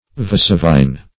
Vesuvine \Ve*su"vine\, n.